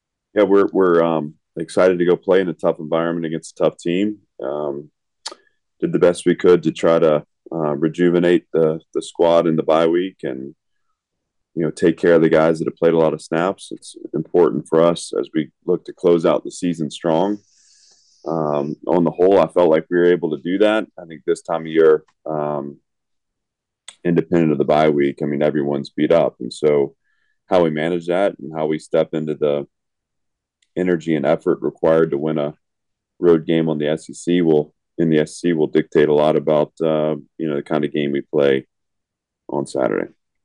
Vanderbilt head coach Clark Lea discusses how his team has been preparing during the bye week:
Lea-on-BYE-week.wav